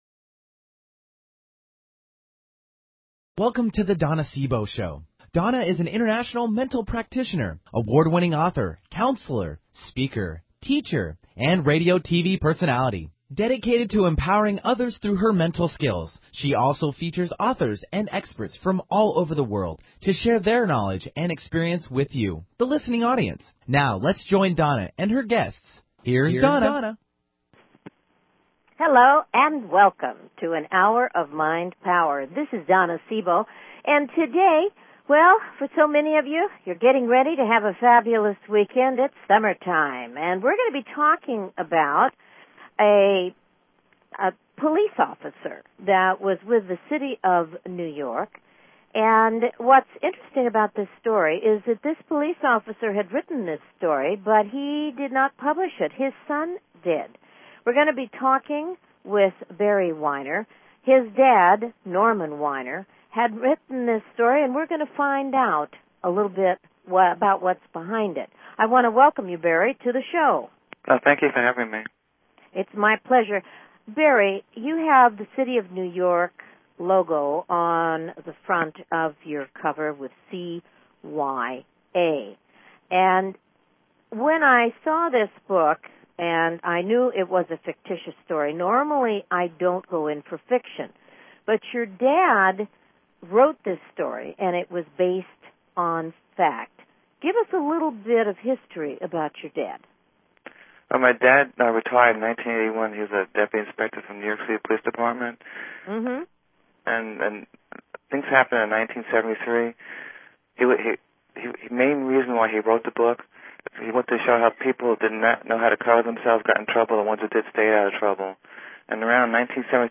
Her interviews embody a golden voice that shines with passion, purpose, sincerity and humor.